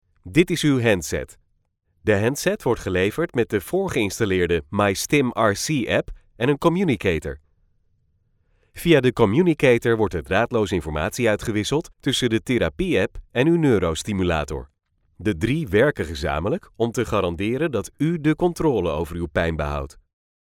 Dutch male voice over.
Dutch male voices